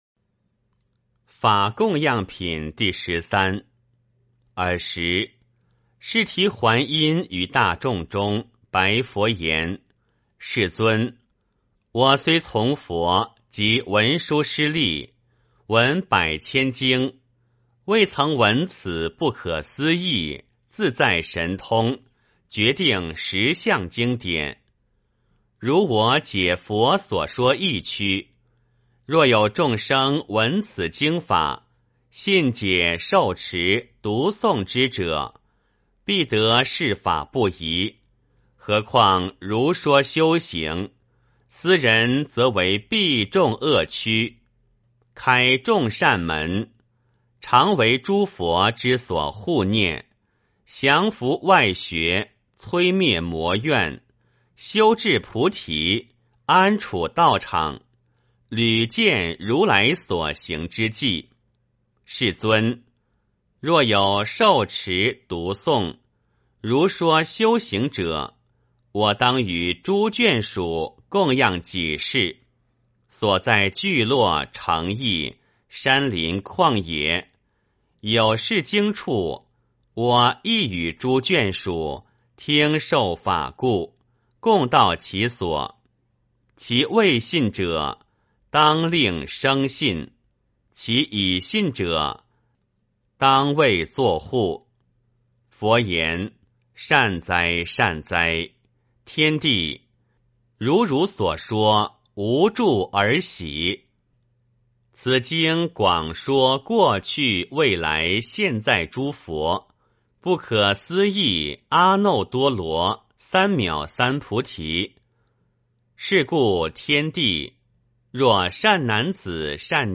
维摩诘经-法供养品第十三 - 诵经 - 云佛论坛